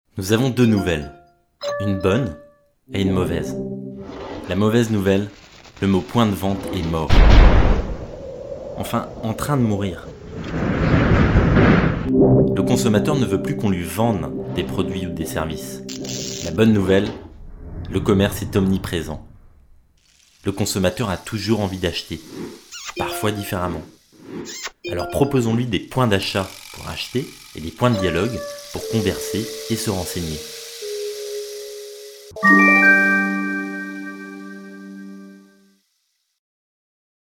Voix off homme grave institutionnel tv
Sprechprobe: Werbung (Muttersprache):
Voice over man medium bass tv